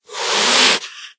hiss3.ogg